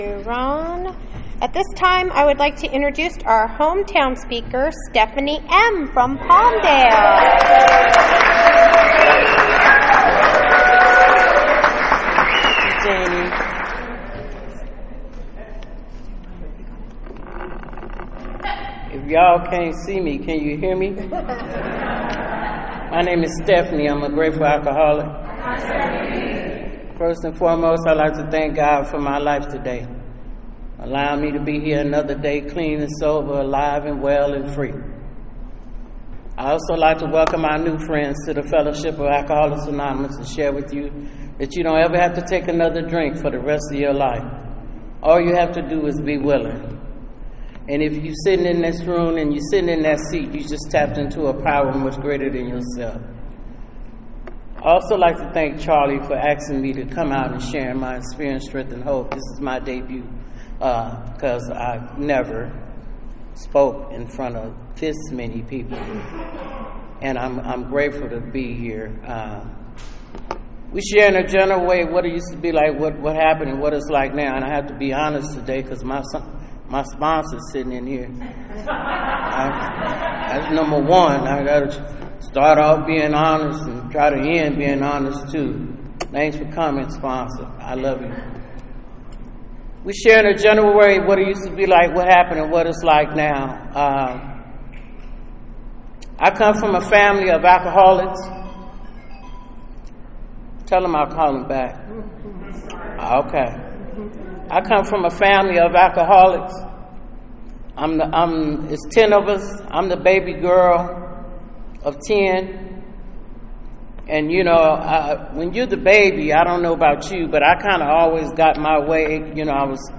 AA - Palmdale CA